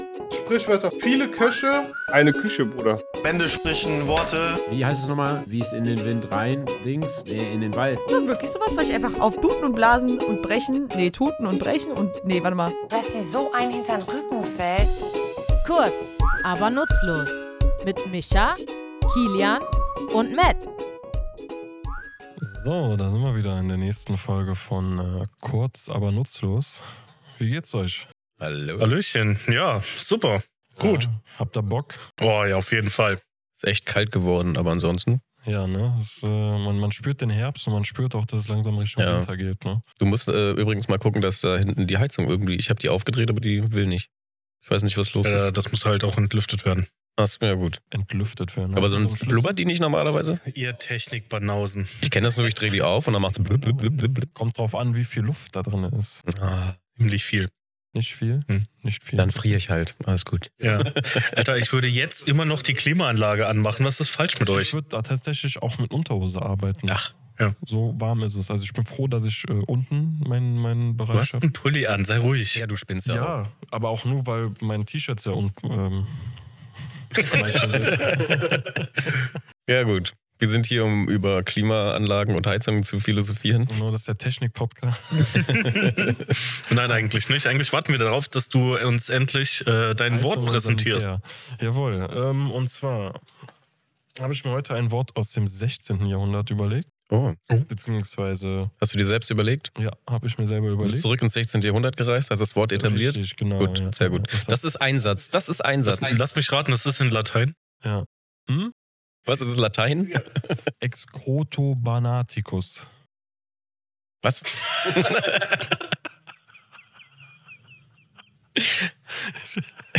Comedy
Wir, drei tätowierende Sprachforscher,